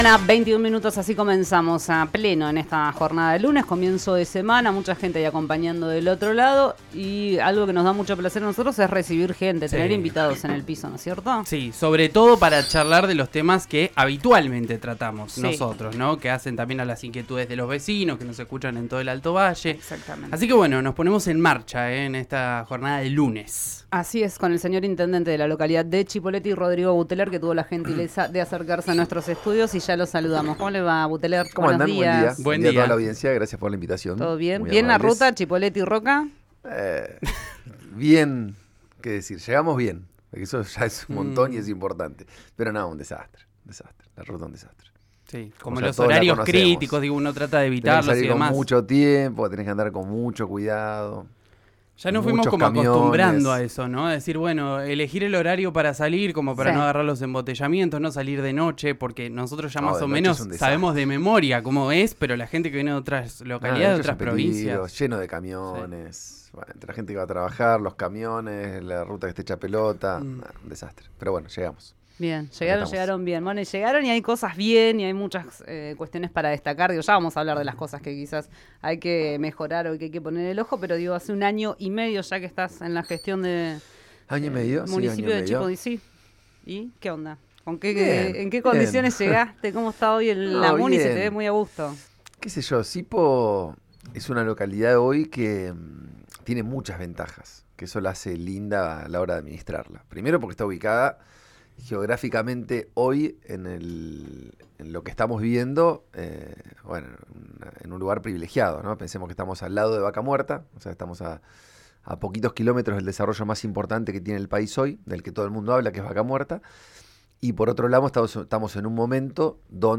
Escuchá la entrevista al intendente de Cipolletti, Rodrigo Buteler en RÍO NEGRO RADIO